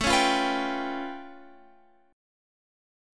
A Augmented 9th